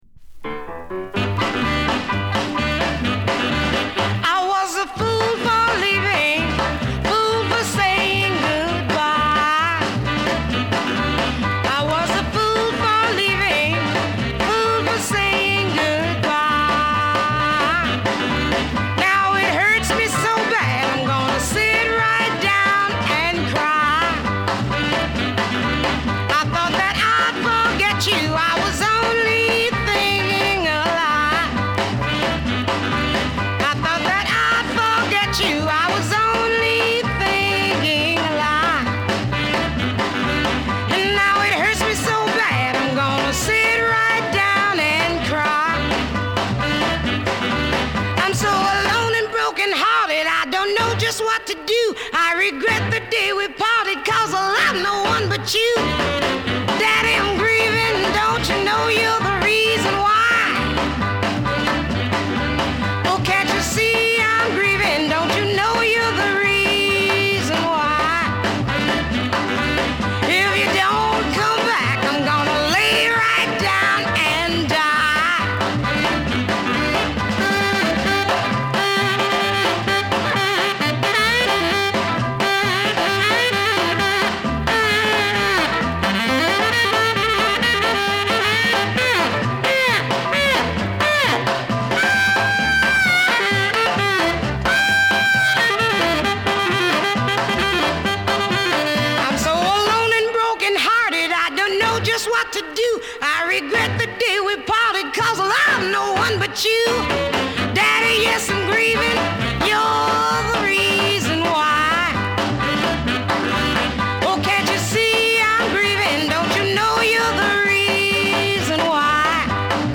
初々しくもやんちゃなヴォーカルがカッコいい。